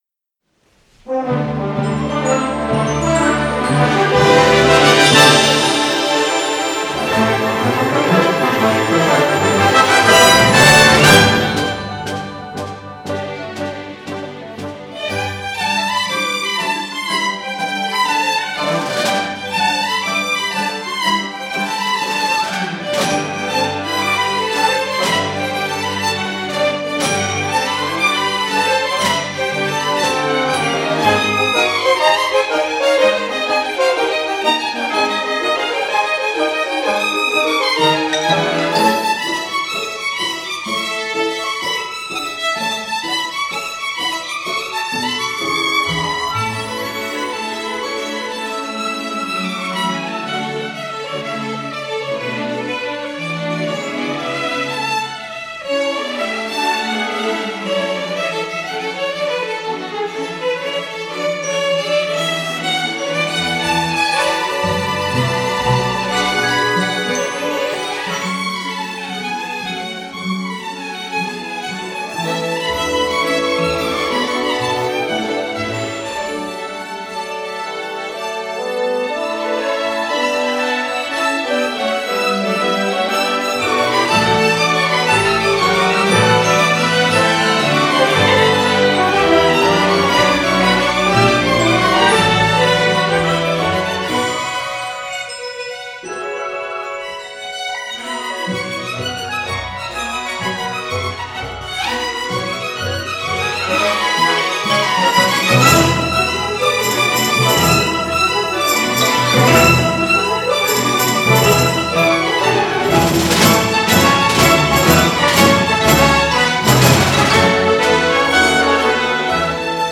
Holiday Pops Music
A rousing arrangement
in the style of an Irish jig